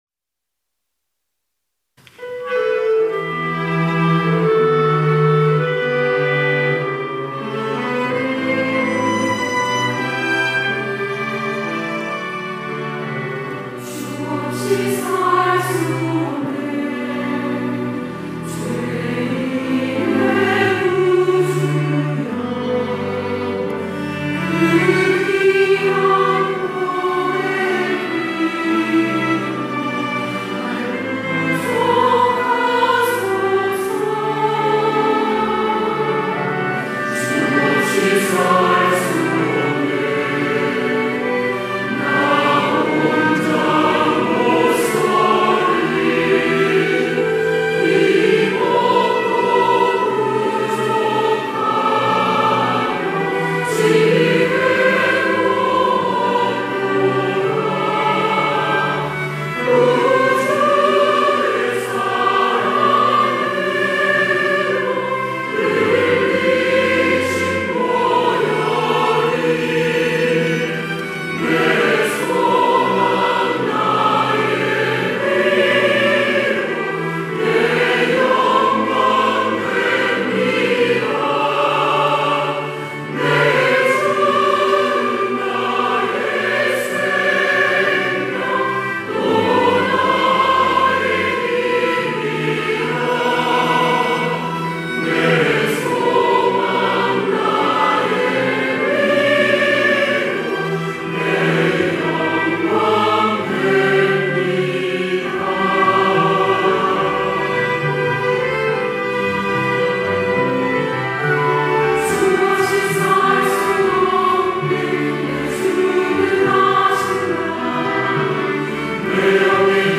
할렐루야(주일2부) - 주 없이 살 수 없네
찬양대